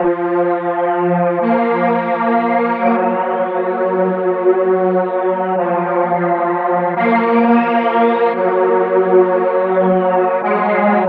Helocon Strings.wav